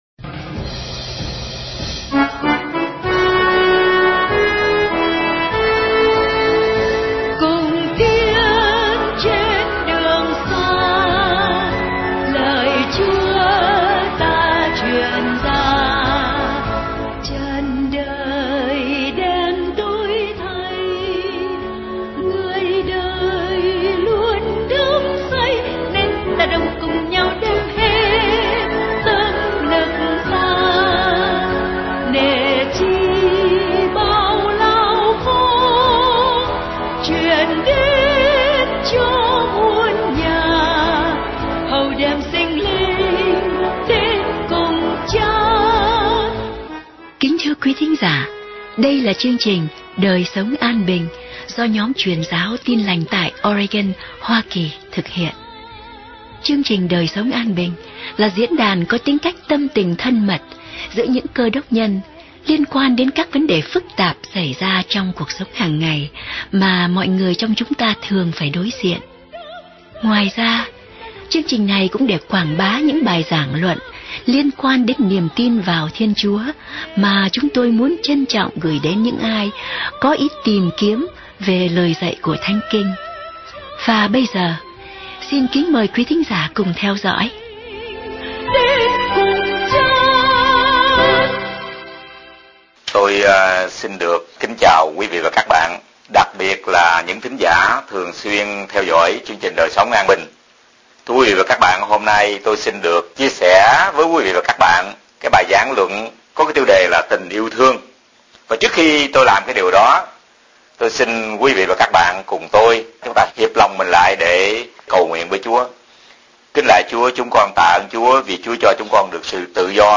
Bài giảng luận 25 phút Đề tài